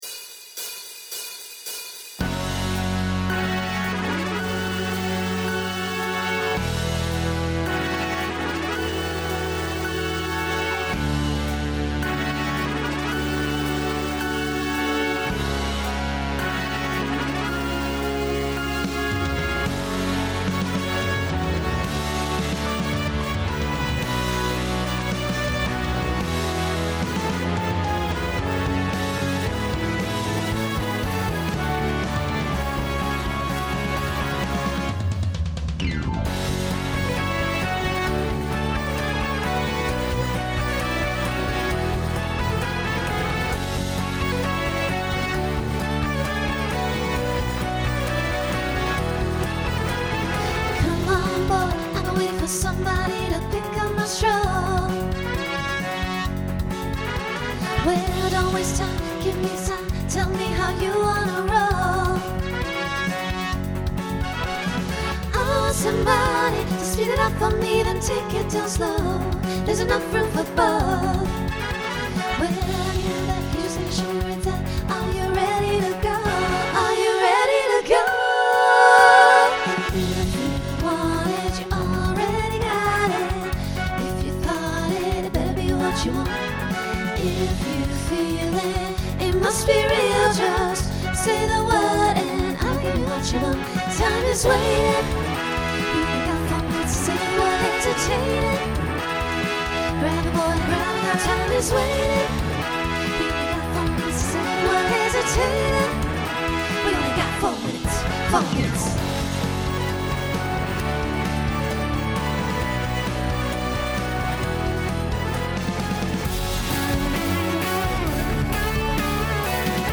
Genre Rock
Opener Voicing SATB , SSA